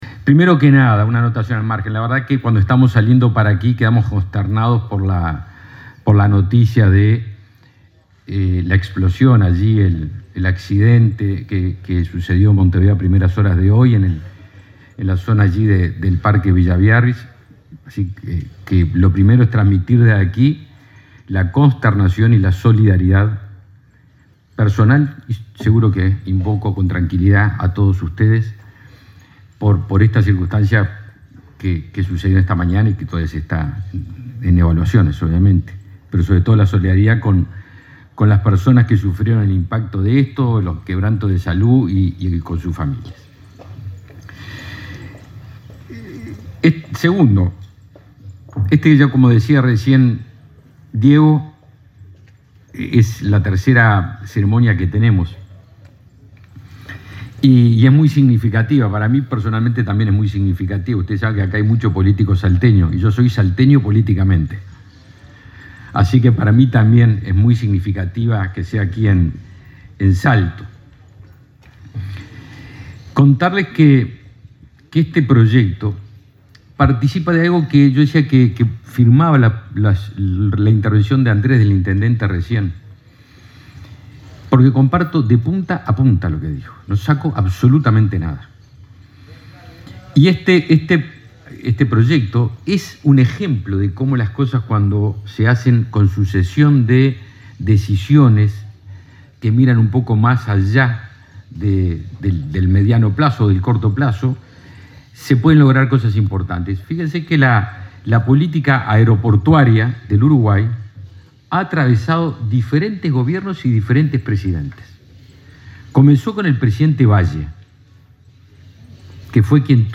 Palabras del ministro de Defensa Nacional, Javier García
Este viernes 22 en Salto, el ministro de Defensa Nacional, Javier García, participó en el acto de traspaso de la gestión del aeropuerto internacional